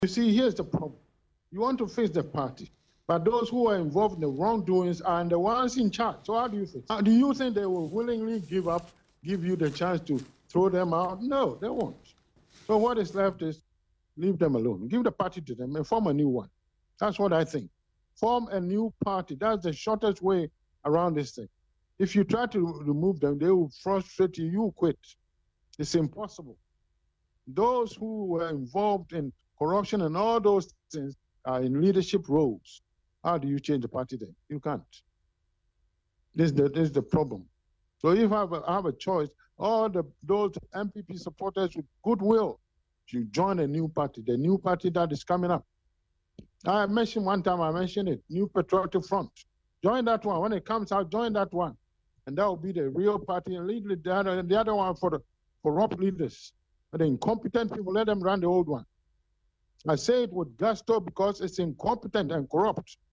Speaking in an interview with Accra-based TV3, the political scientist explained that the NPF would emerge as an alternative born out of the NPP and called on all well-meaning Ghanaians to rally behind it.